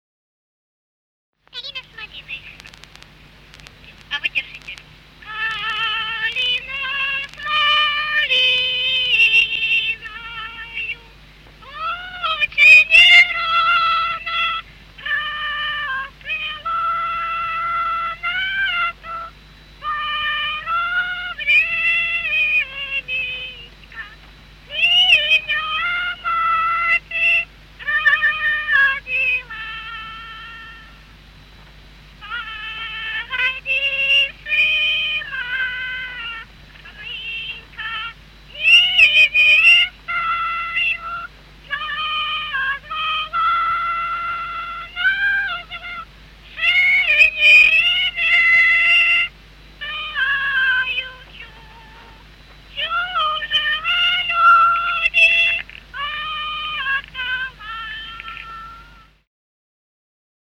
Русские народные песни Владимирской области [[Описание файла::27. Калина с малиной (лирическая) с. Сельцо Суздальского района Владимирской области.